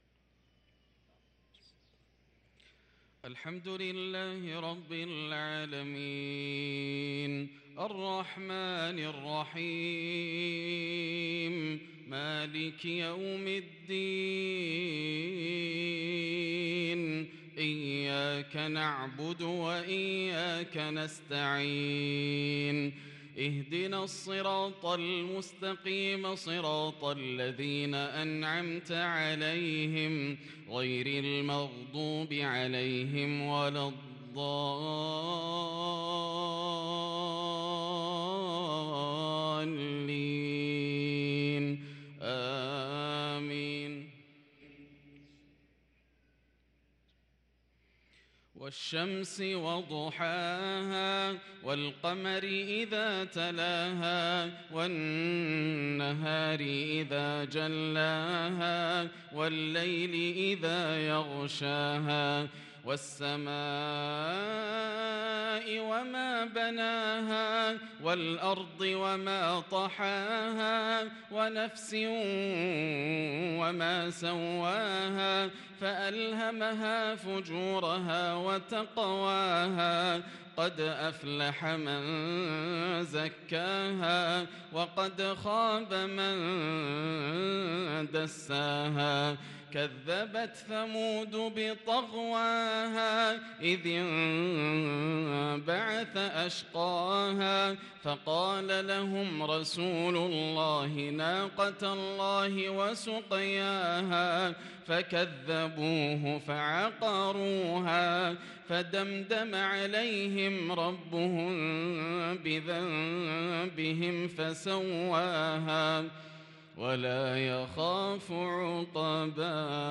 صلاة المغرب للقارئ ياسر الدوسري 10 جمادي الأول 1444 هـ
تِلَاوَات الْحَرَمَيْن .